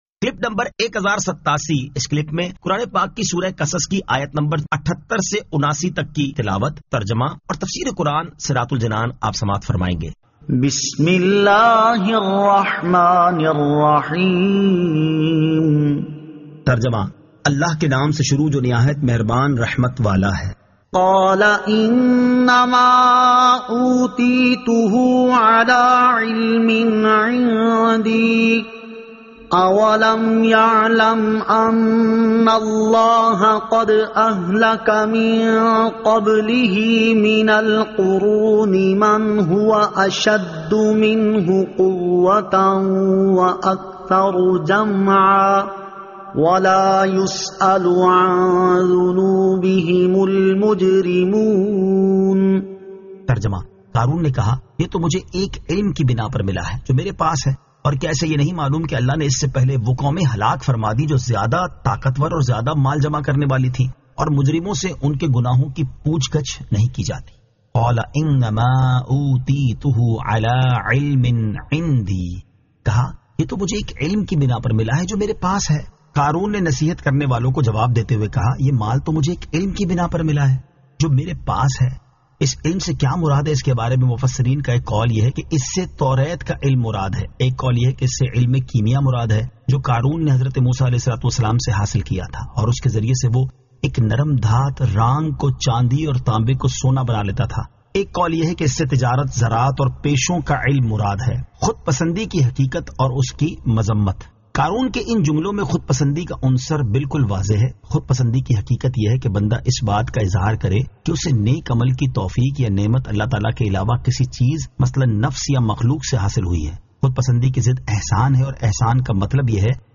Surah Al-Qasas 78 To 79 Tilawat , Tarjama , Tafseer
2022 MP3 MP4 MP4 Share سُوَّرۃُ الْقَصَصٗ آیت 78 تا 79 تلاوت ، ترجمہ ، تفسیر ۔